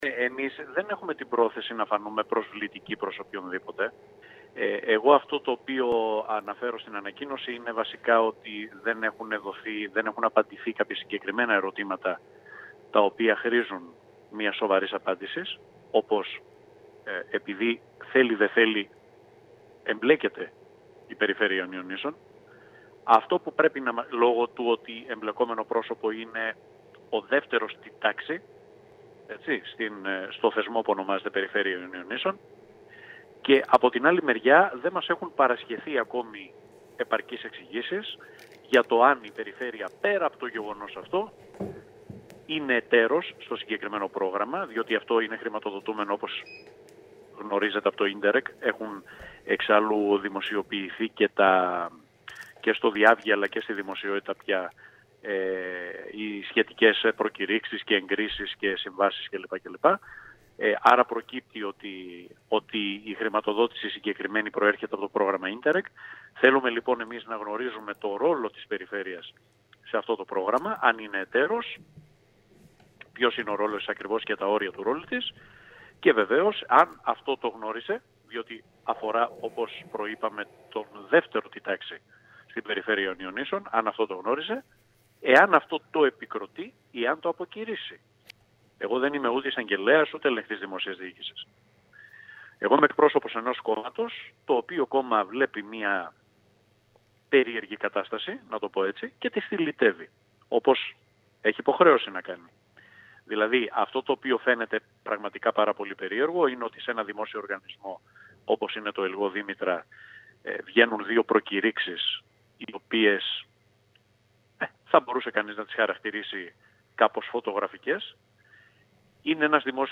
Κέρκυρα: Δηλώσεις